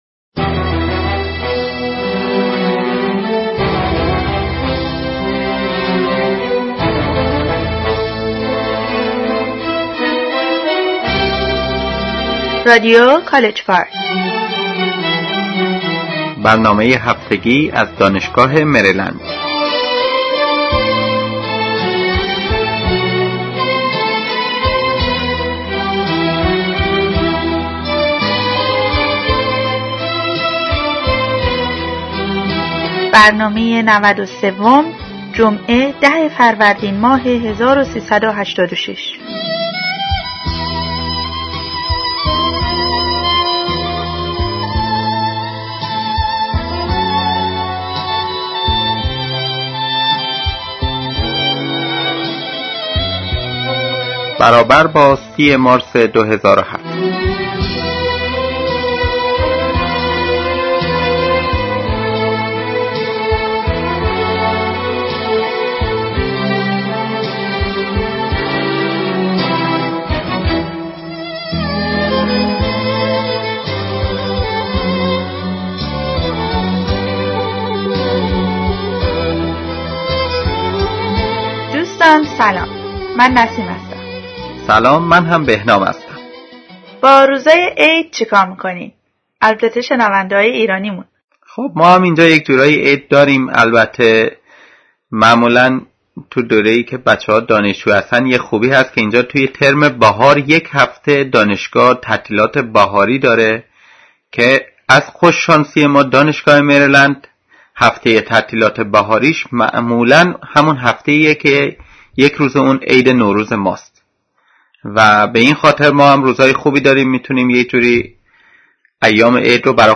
Weekly News